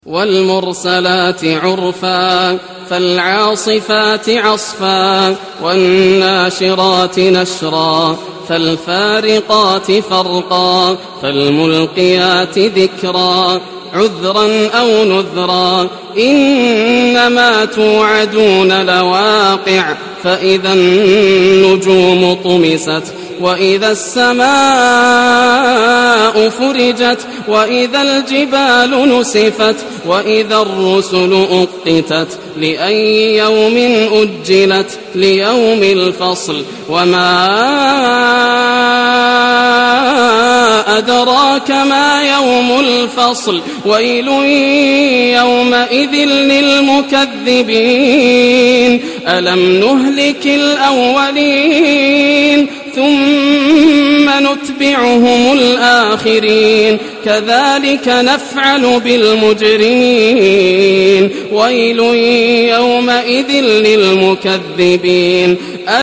القارىء: ياسر الدوسري